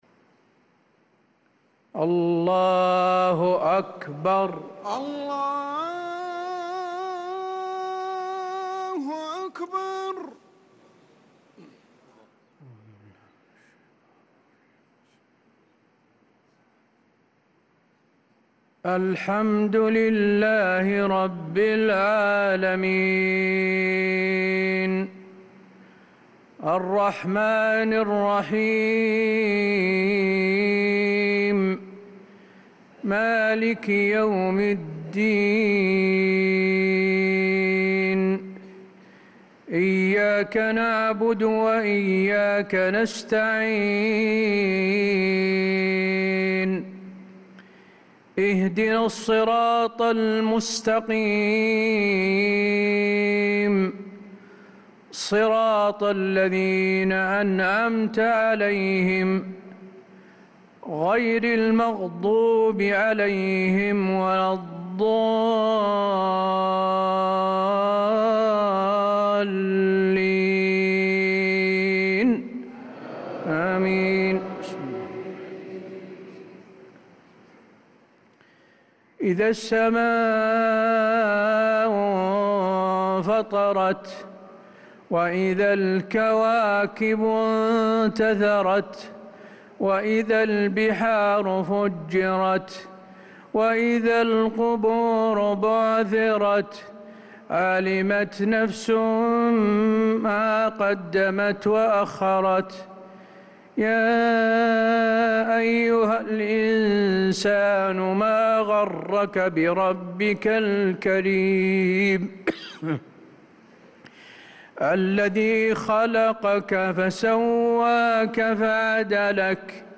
صلاة المغرب للقارئ حسين آل الشيخ 23 محرم 1446 هـ
تِلَاوَات الْحَرَمَيْن .